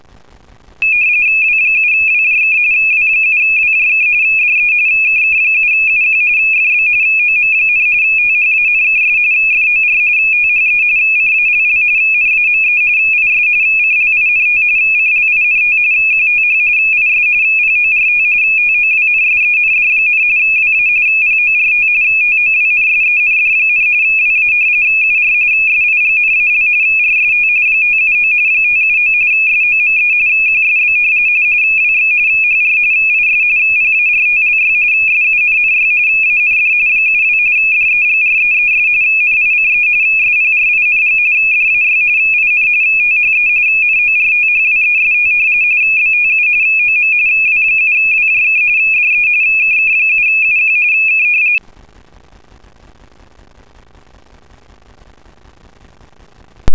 Ecco di seguito come suonano i vari modi:
rtty
rttyA.wav